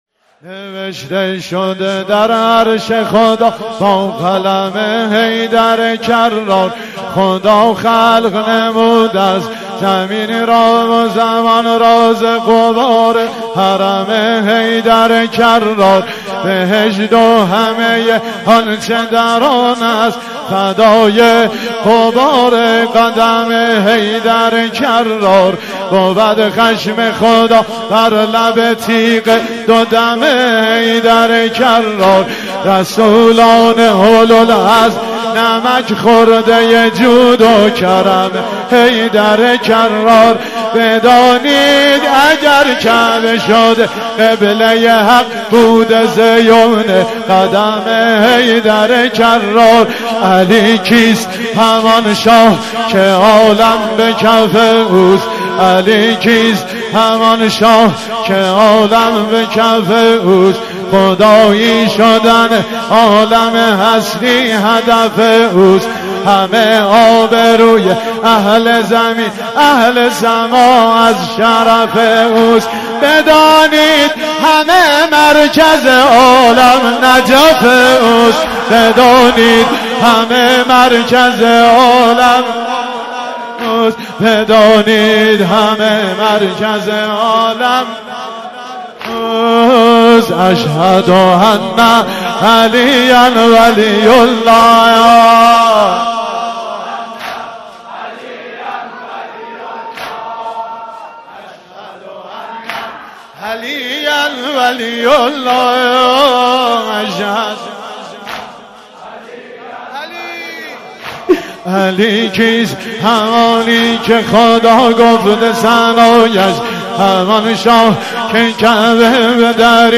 مناسبت : وفات حضرت زینب سلام‌الله‌علیها